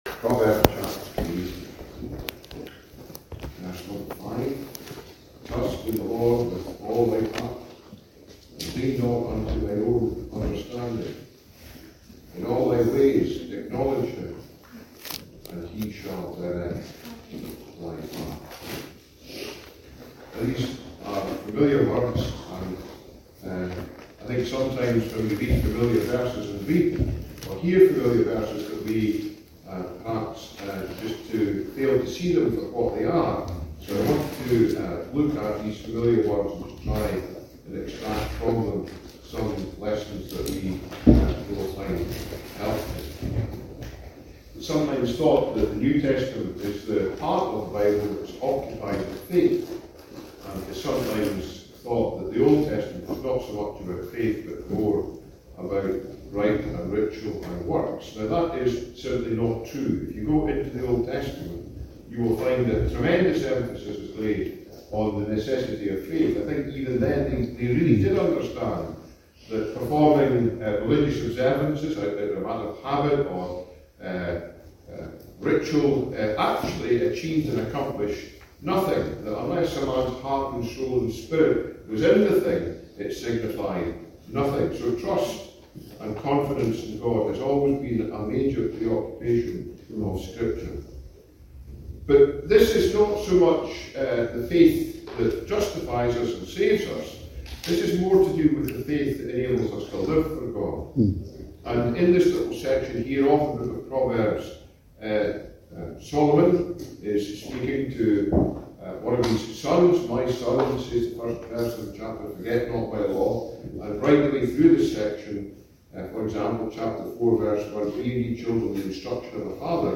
In this challenging and encouraging sermon